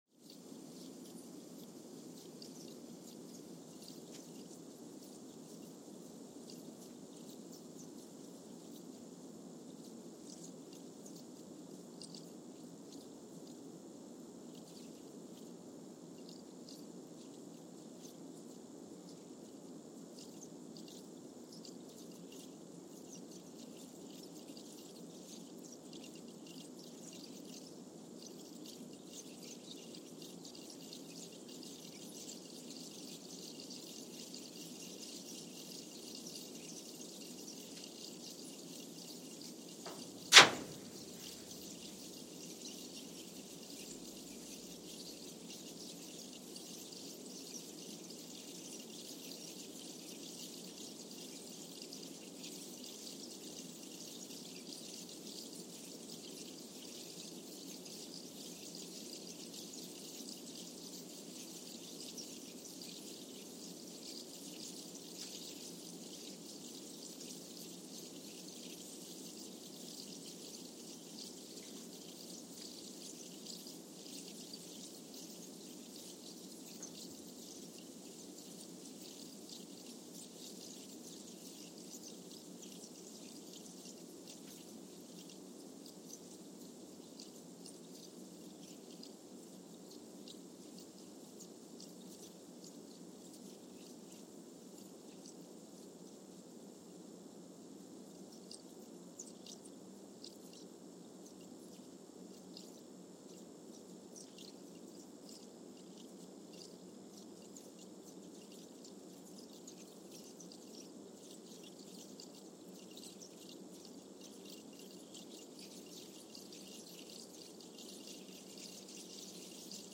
San Juan, Puerto Rico (seismic) archived on February 4, 2023
Station : SJG (network: IRIS/USGS) at San Juan, Puerto Rico
Sensor : Trillium 360
Speedup : ×1,000 (transposed up about 10 octaves)
Loop duration (audio) : 05:45 (stereo)
Gain correction : 25dB
SoX post-processing : highpass -2 90 highpass -2 90